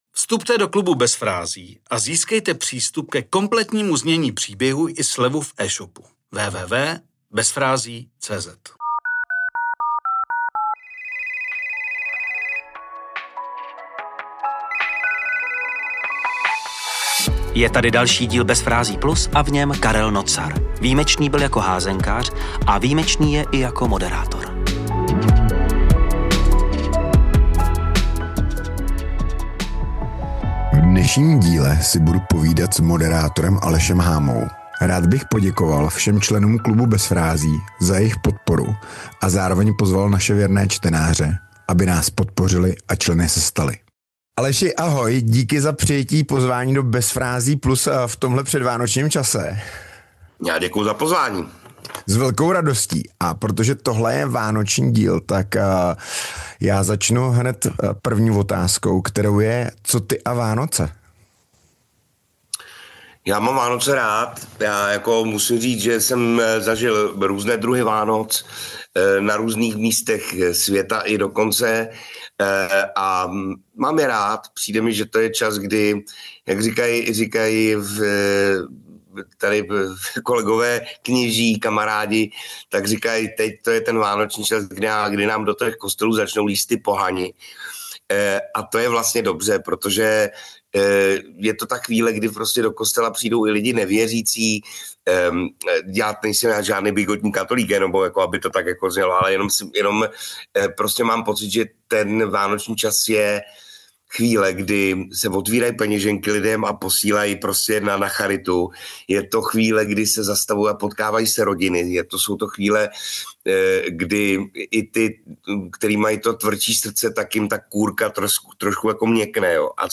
🎧 CELÝ ROZHOVOR pouze pro členy KLUBU BEZ FRÁZÍ.